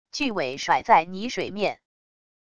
巨尾甩在泥水面wav音频